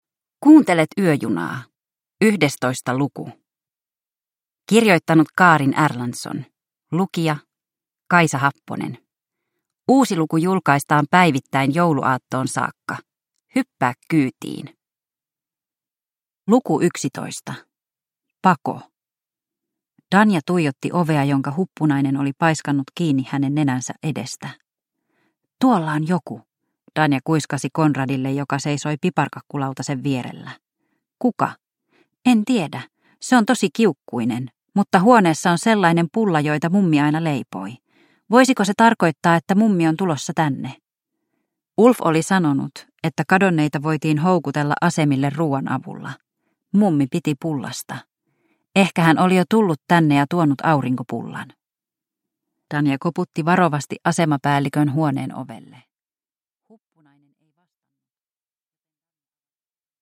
Yöjuna luku 11 – Ljudbok